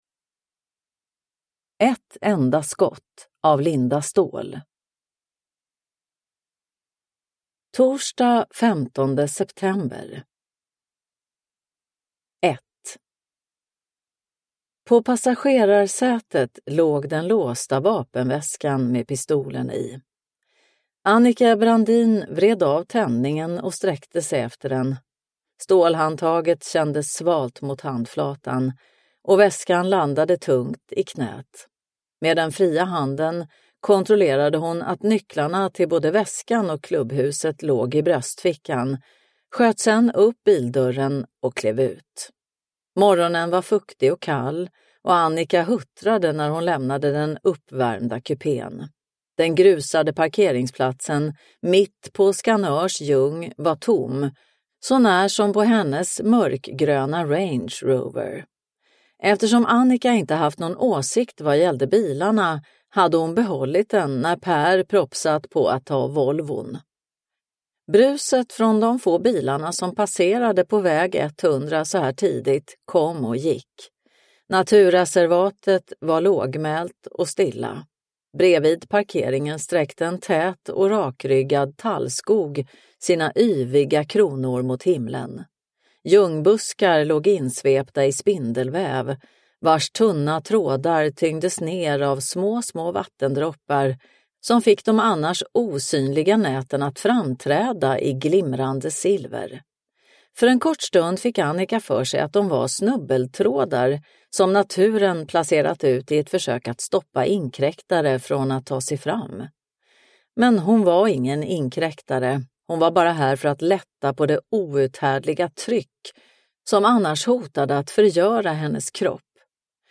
Ett enda skott – Ljudbok – Laddas ner